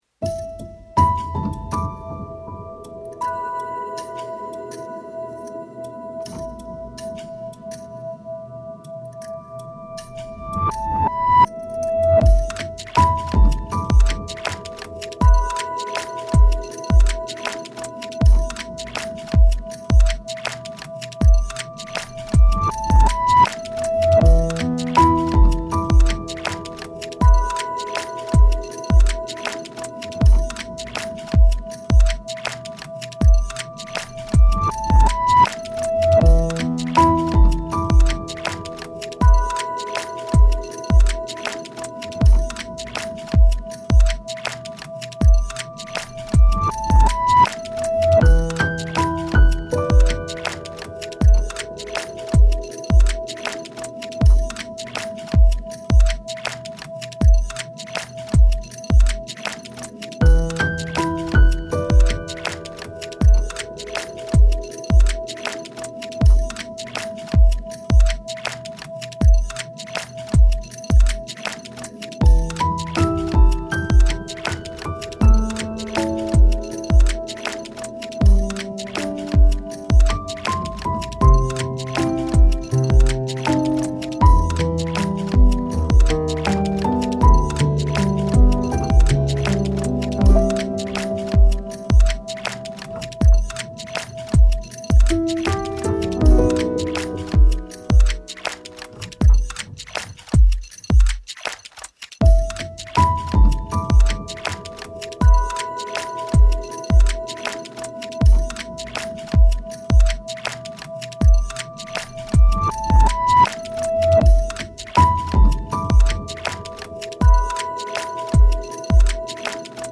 儚くて繊細な音楽素材
アコースティック 3:36